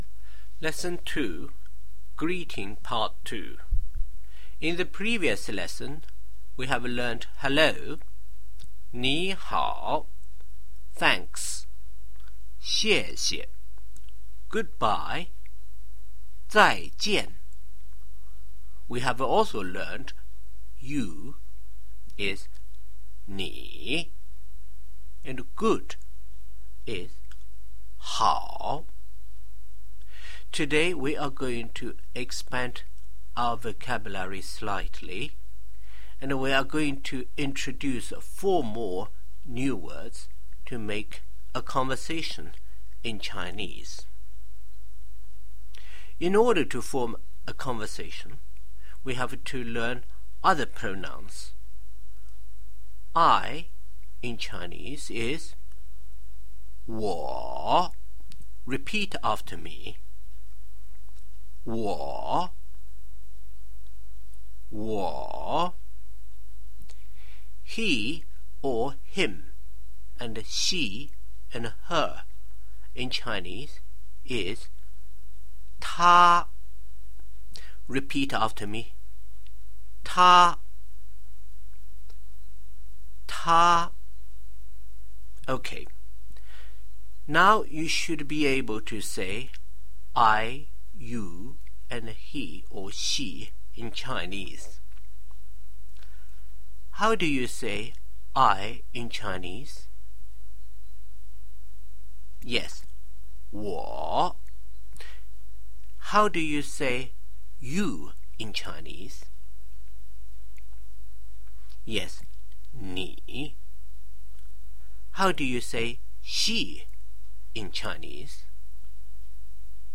Lesson Two Greeting Part 2